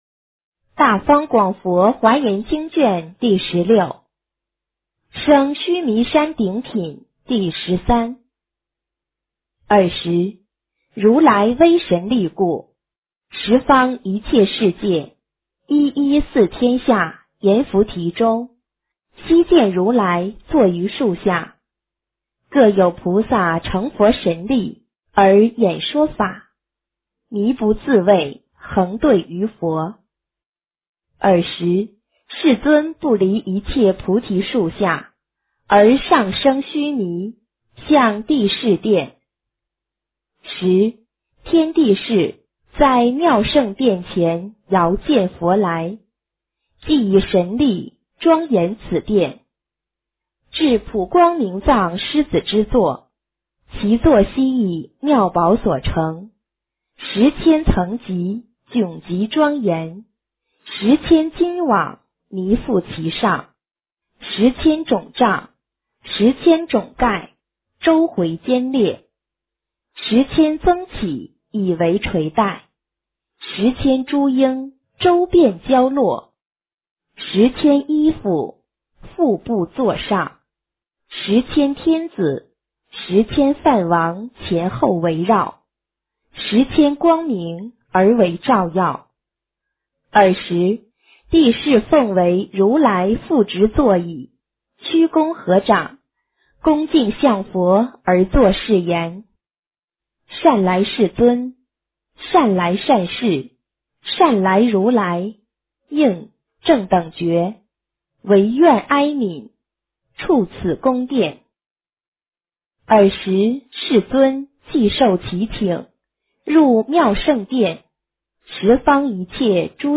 华严经16 - 诵经 - 云佛论坛